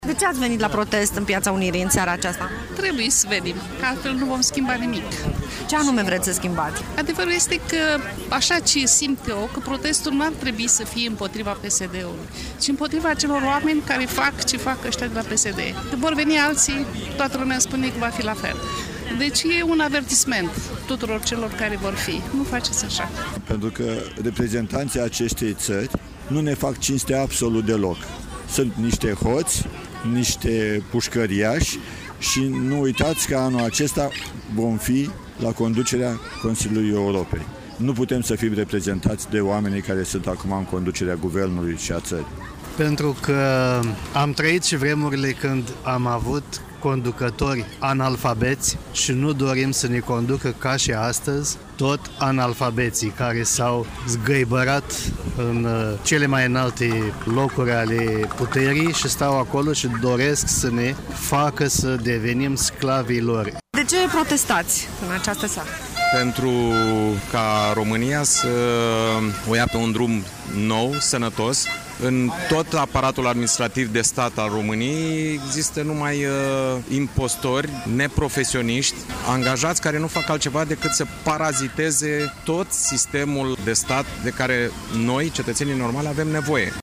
Aceștia s-au adunat în Piața Unirii, unde au scandat „Nu se mai poate, vrem anticipate!”, „Jos Guvernul”, „Uniți salvăm toată România!”.
12-aug-ora-20-Vox-protestatari-Iasi.mp3